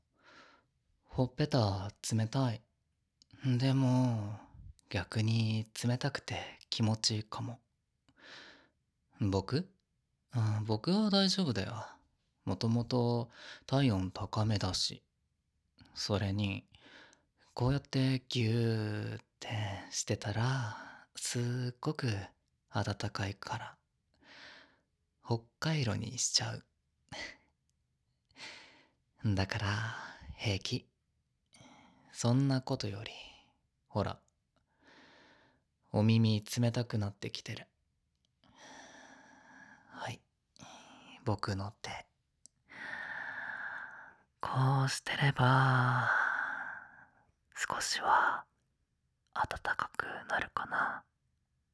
バイノーラルマイク使用のため、イヤホンやヘッドホン推奨となります。 機材：3Dio Free Space XLR